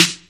OLDSCHOOL_SNR.wav